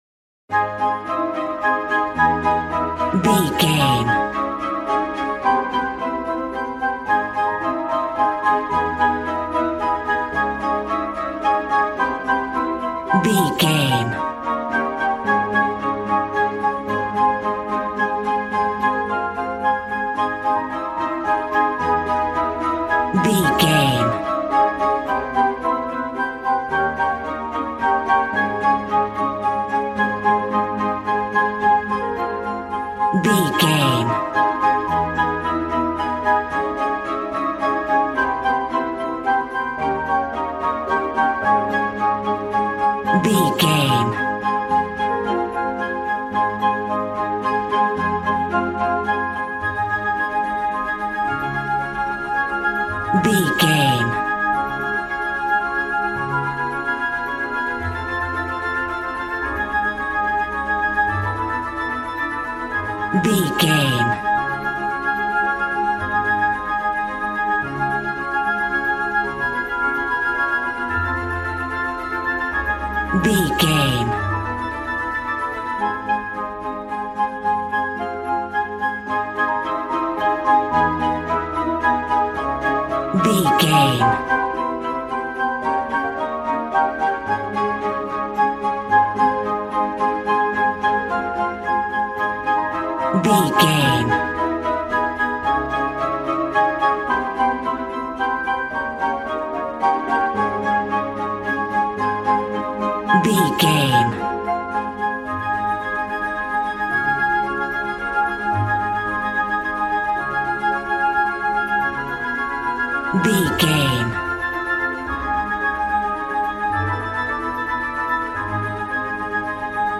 Regal and romantic, a classy piece of classical music.
Ionian/Major
A♭
regal
strings
violin
brass